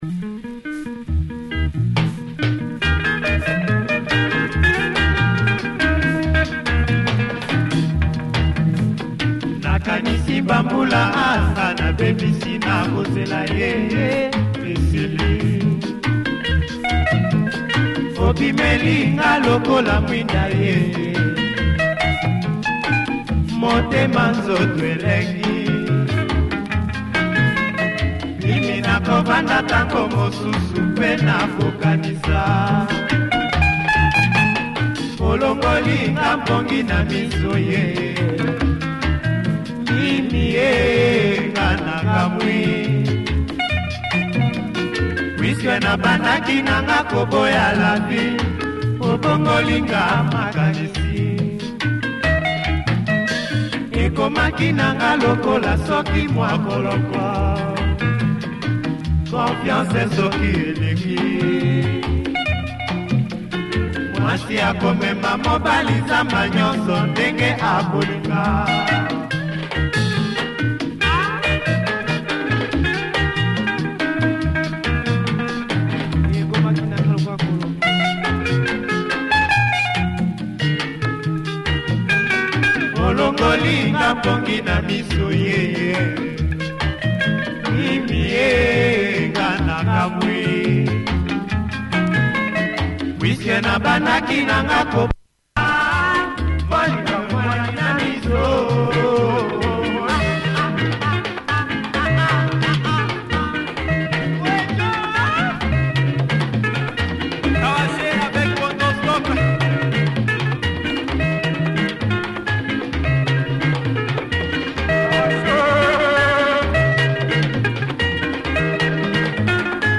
Party number
great breakdown
good drums!